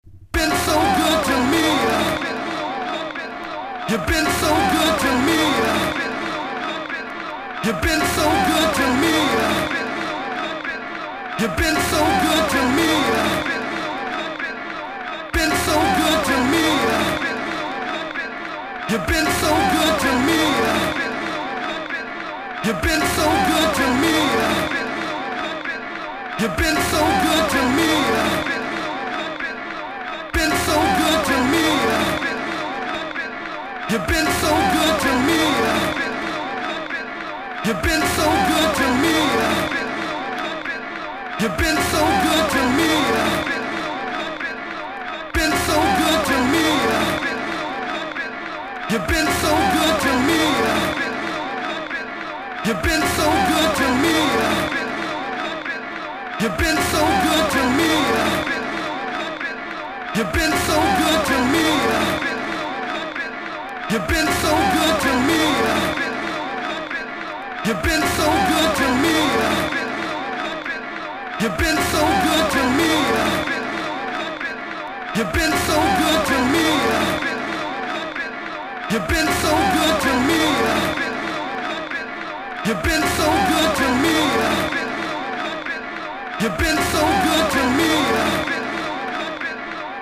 日本流通盤のマッドなサウンドエフェクトがついたうれしい仕様となってます！